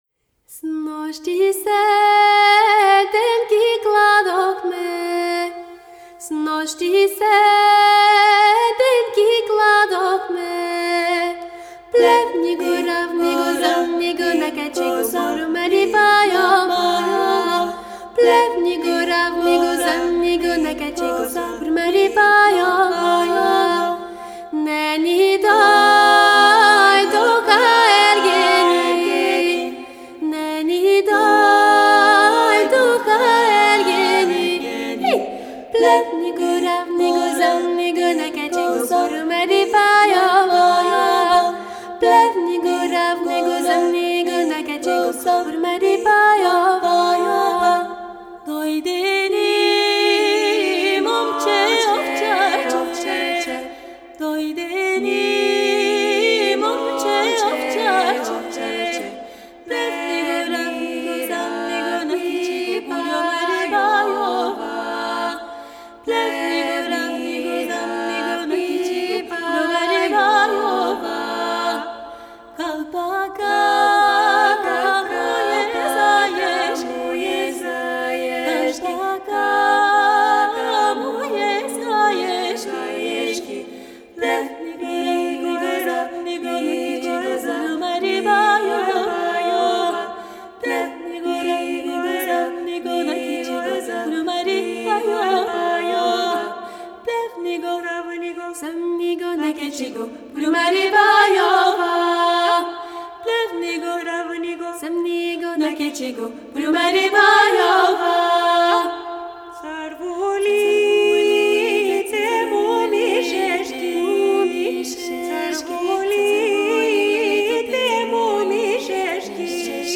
Genre: World, Balkan Music